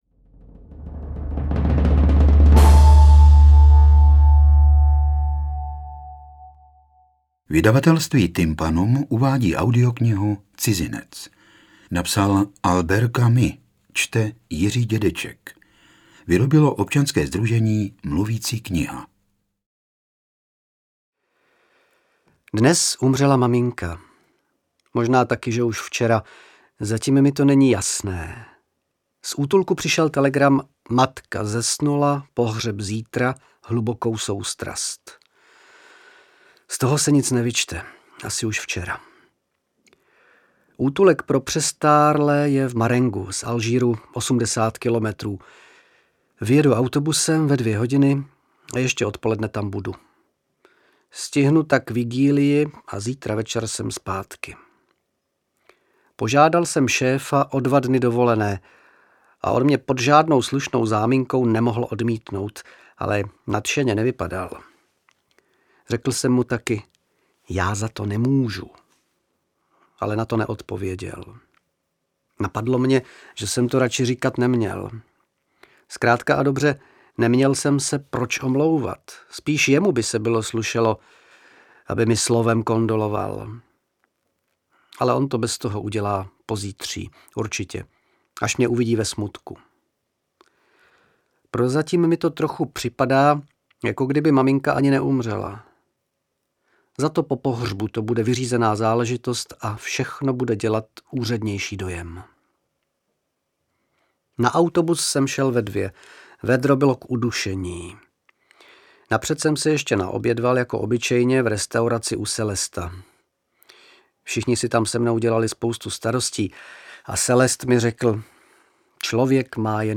Interpret:  Jiří Dědeček